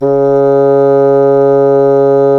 Index of /90_sSampleCDs/Roland LCDP04 Orchestral Winds/CMB_Wind Sects 1/CMB_Wind Sect 7
WND BSSN C#3.wav